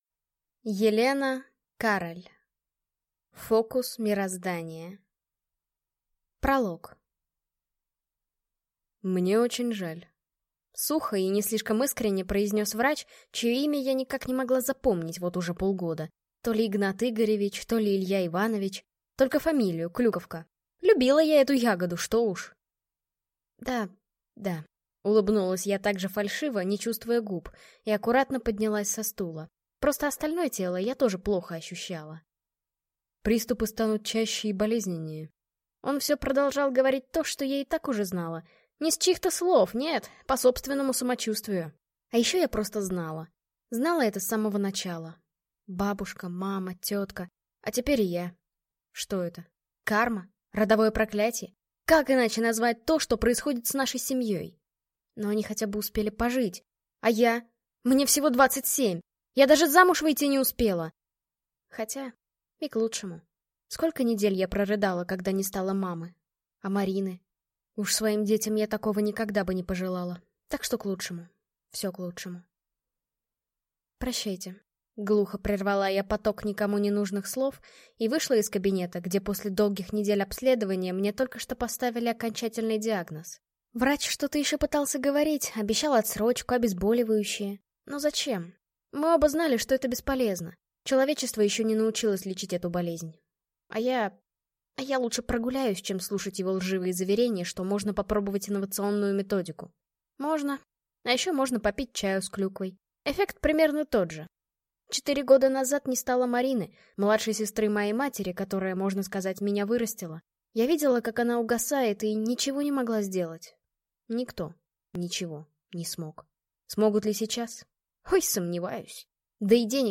Аудиокнига Фокус мироздания | Библиотека аудиокниг